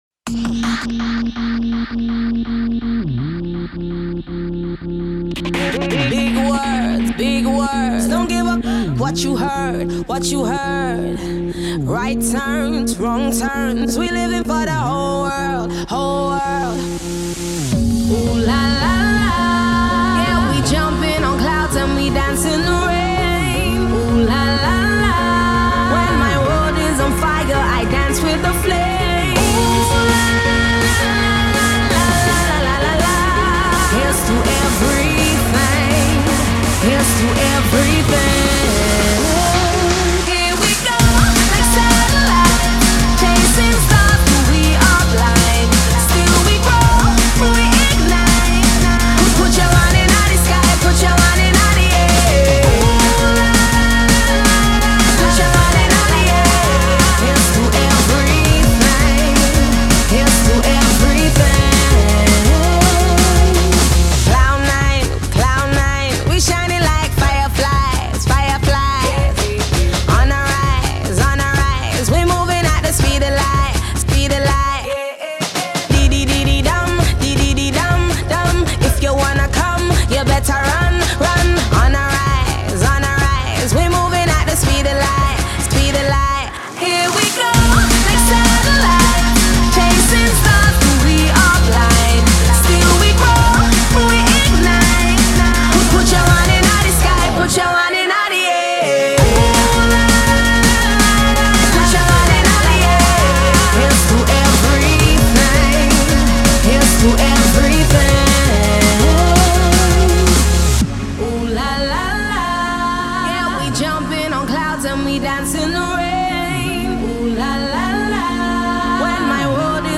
a feel good celebration of a POP single.